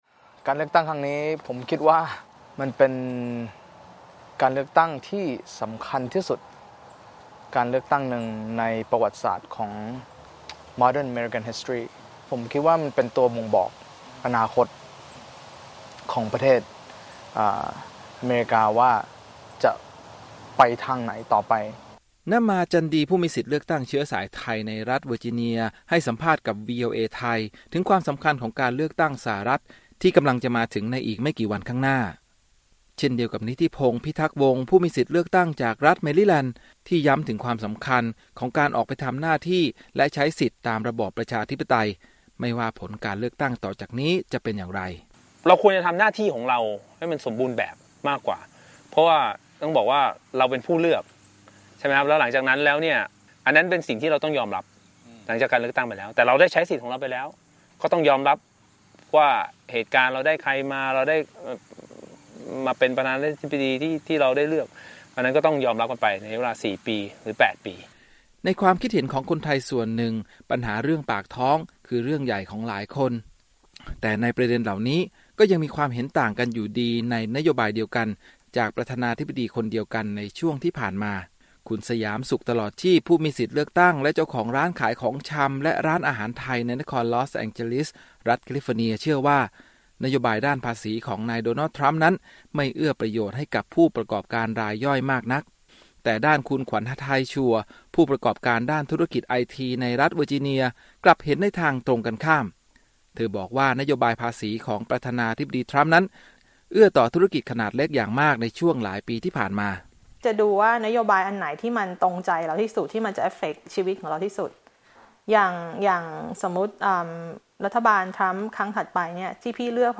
รายงานพิเศษ : ฟังเสียงผู้มีสิทธิ์เลือกตั้งเชื้อสายไทยในอเมริกา
ฟังหลากมุมมองและเสียงสะท้อนที่แตกต่างของผู้มีสิทธิเลือกตั้งเชื้อสายไทยในสหรัฐฯที่มีต่อการเลือกตั้งสหรัฐฯที่กำลังจะมาถึงในไม่กี่วันข้างหน้า ติดตามจากรายงานพิเศษ เกาะติดเลือกตั้งสหรัฐฯ 2020 ของจากวีโอเอ ภาคภาษาไทย